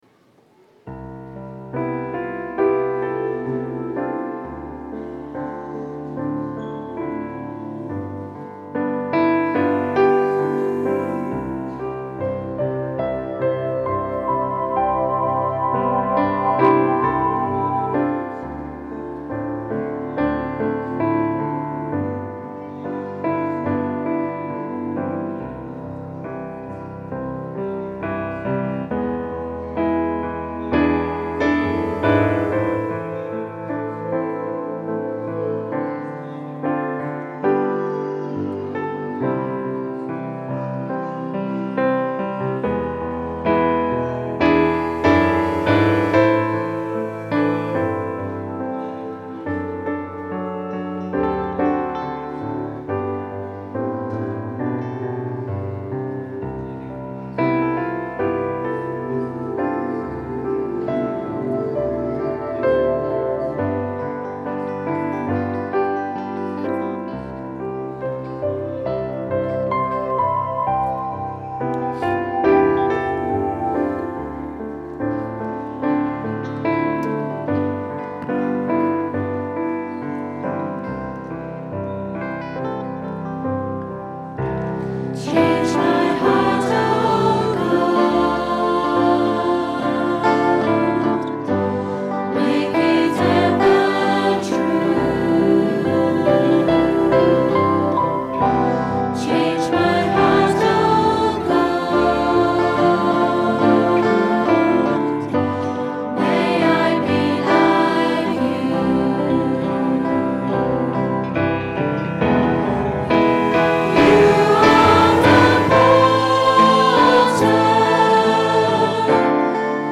Recorded on a Zoom H4 digital stereo recorder at 10am Mass Sunday 4th July 2010.